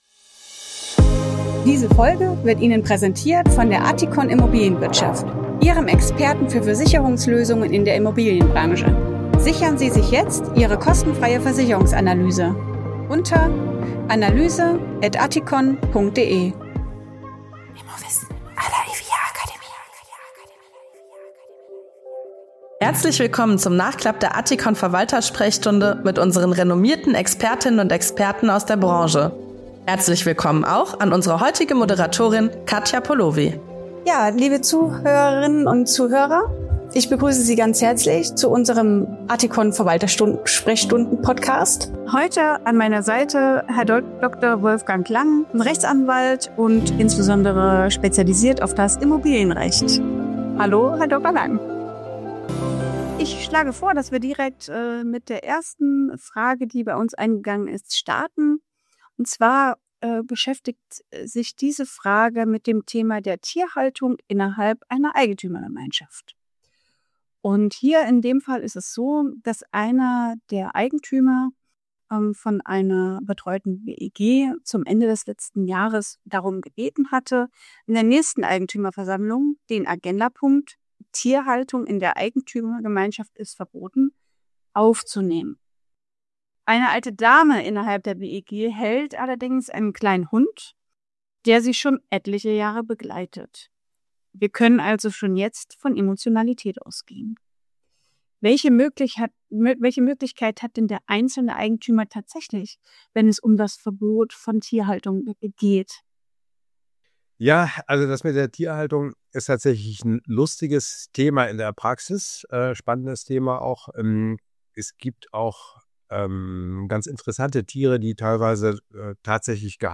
Expertenrunde in dieser Folge